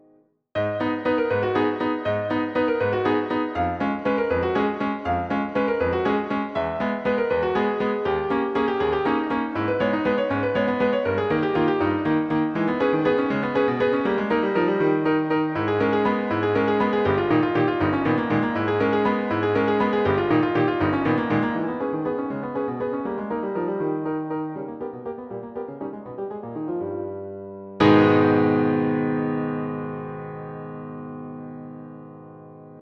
Etude no.3 - Piano Music, Solo Keyboard - Young Composers Music Forum